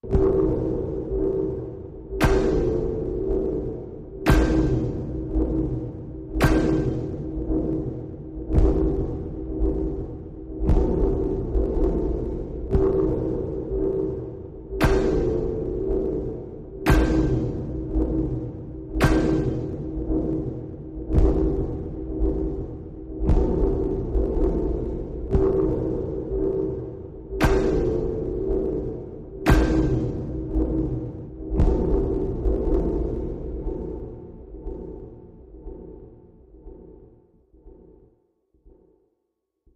Spark Arrester, Machine, Low Sparks, Pulse Drone, Electric Hits, Atmosphere